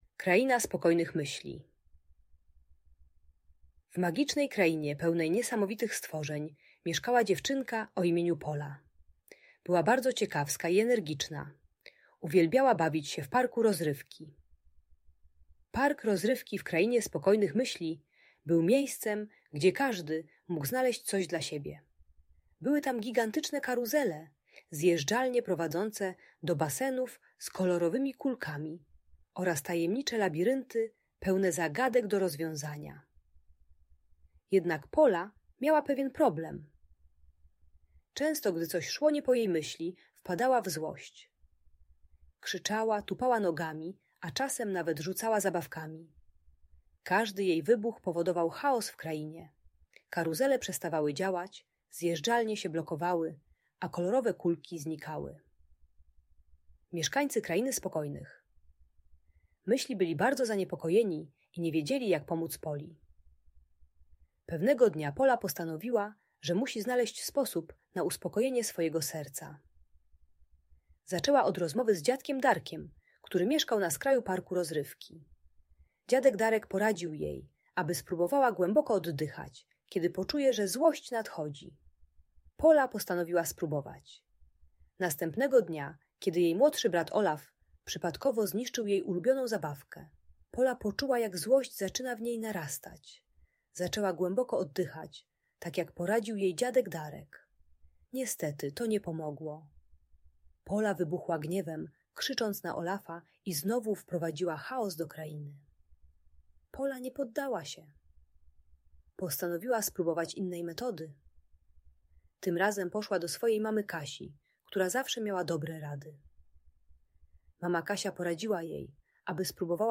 Historia o radzeniu sobie ze złością w Krainie Spokojnych Myśli - Audiobajka